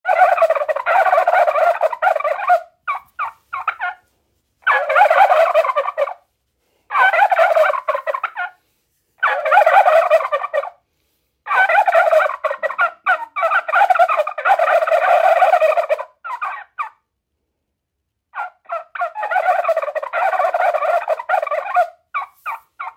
Turkey Car (QSI System)
Hear Turkeys Gobble
Gobble.m4a